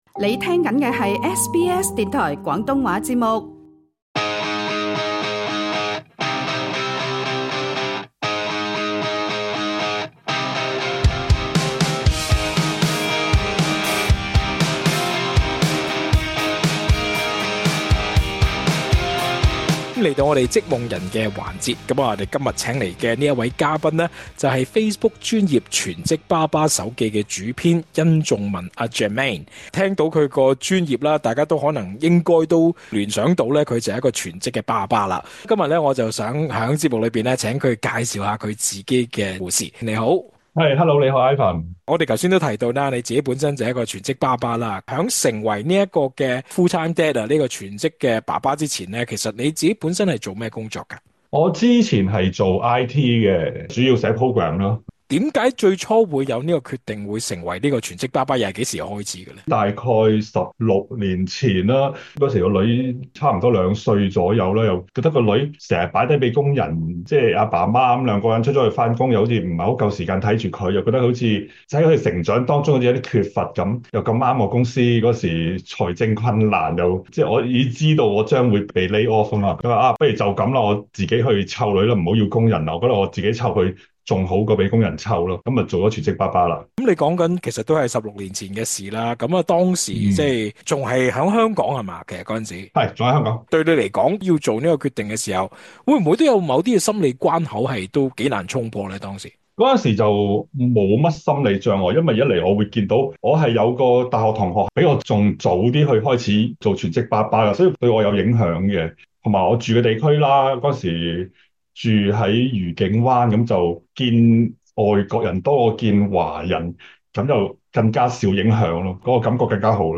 更多訪問詳情，請留意足本錄音。